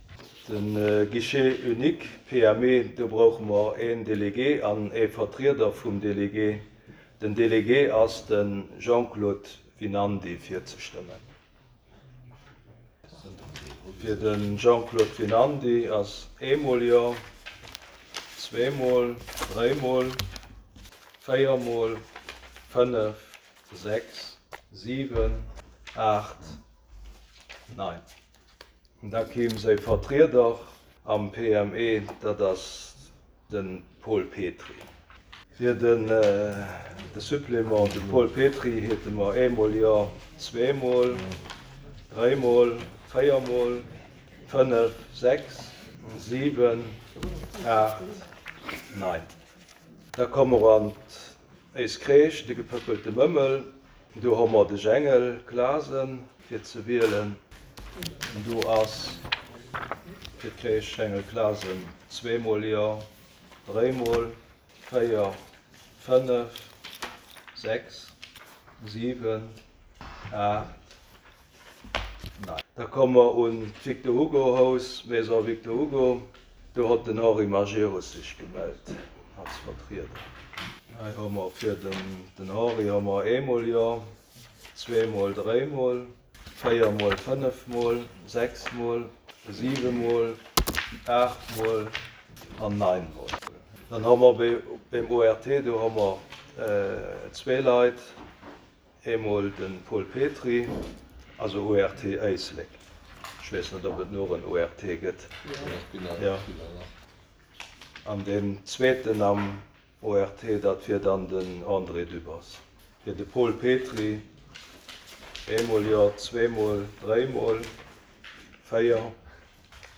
Conseil Communal du mercredi,19 juillet 2023 à 14.00 heures en la salle Bessling du Centre Culturel Larei